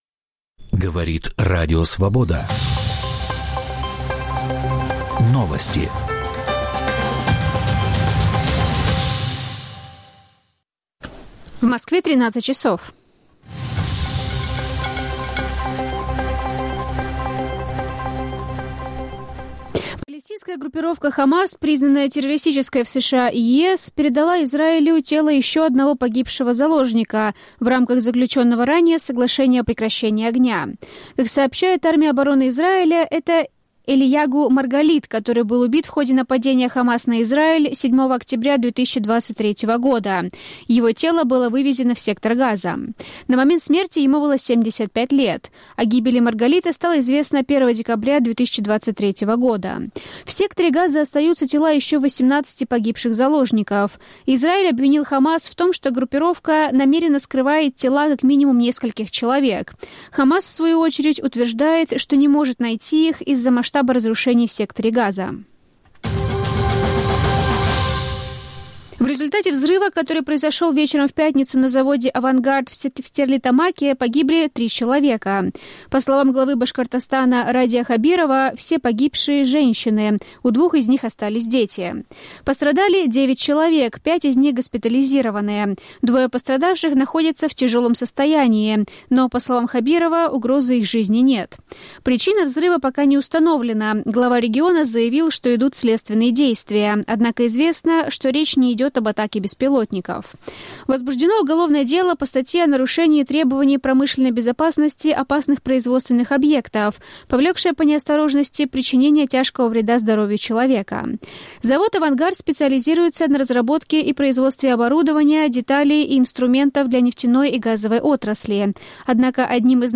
Выпуск новостей Радио Свобода:
Аудионовости